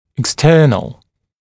[ɪk’stɜːnl] [ek-] [ик’стё:нл] [эк-] внешний, наружный